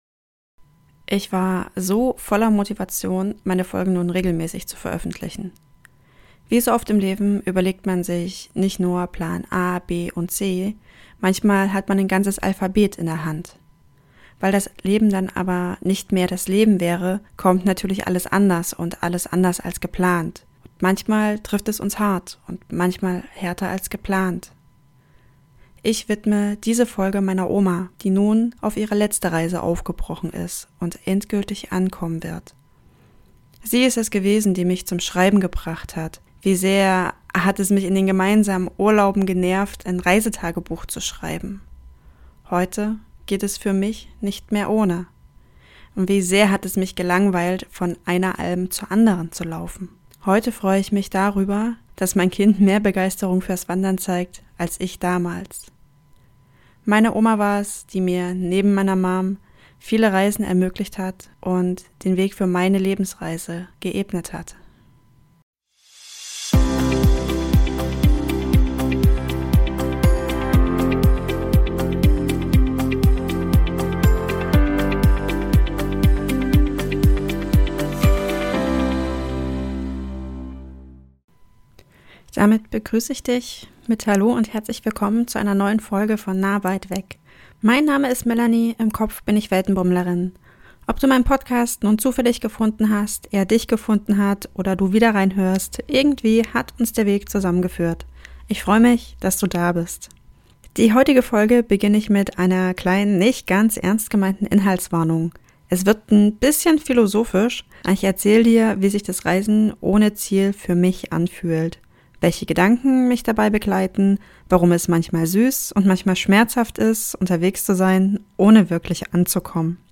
Diese Folge ist persönlich, ruhig und stellenweise philosophisch.